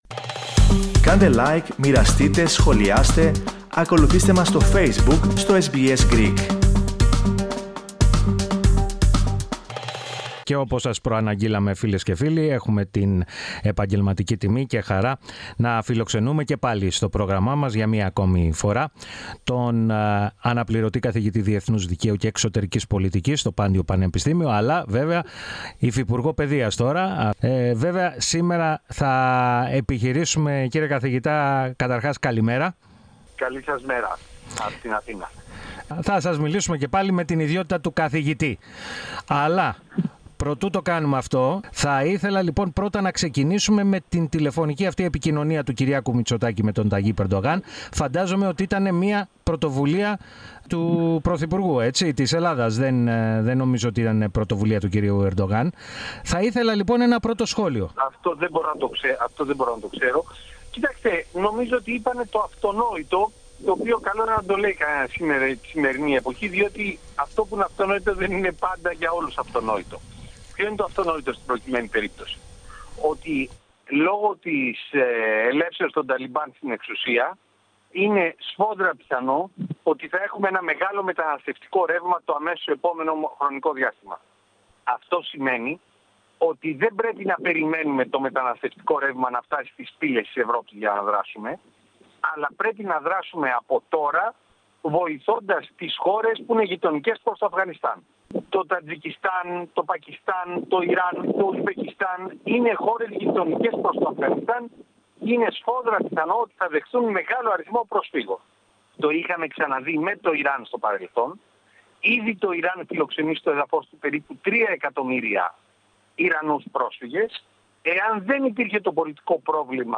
Αυτό, τόνισε, μεταξύ άλλων, ο αναπληρωτής καθηγητής Διεθνούς Δικαίου και Εξωτερικής Πολιτικής, στο Πάντειο Πανεπιστήμιο, και υφυπουργός Παιδείας, Άγγελος Συρίγος, ο οποίος μίλησε στο Ελληνικό Πρόγραμμα της ραδιοφωνίας SBS.